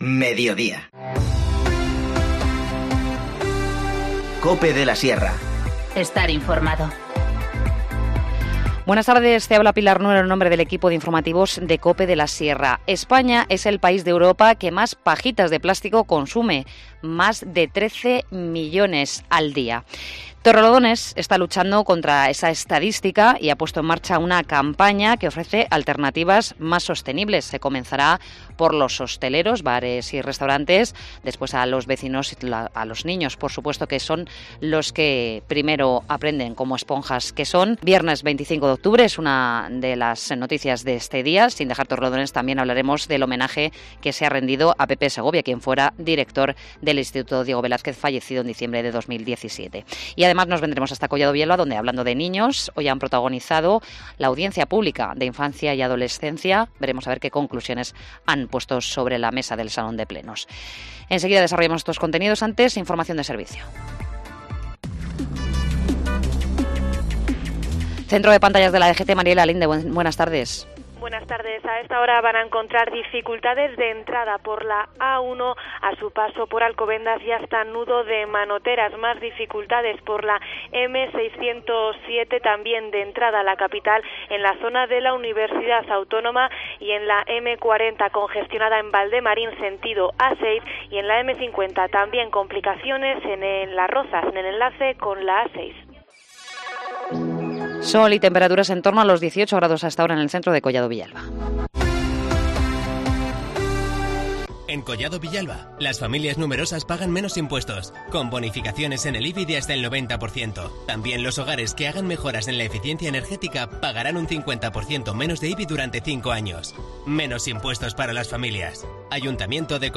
Informativo Mediodía 25 octubre 14:20h
INFORMACIÓN LOCAL